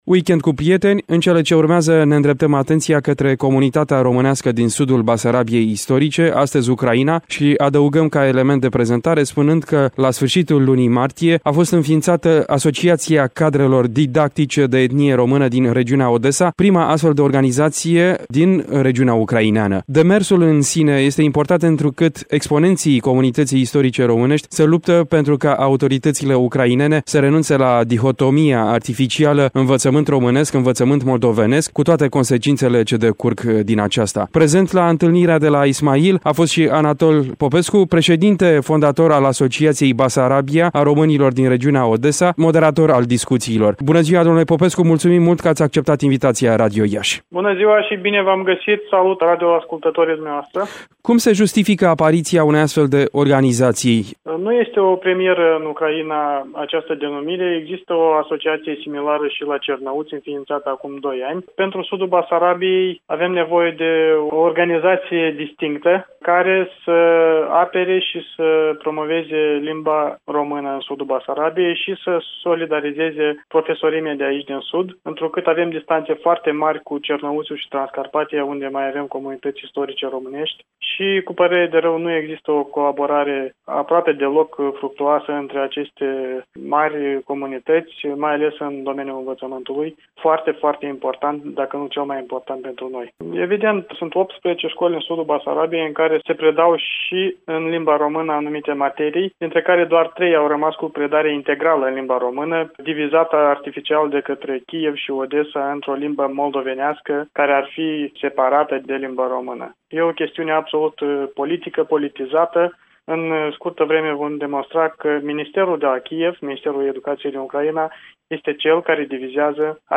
Într-un dialog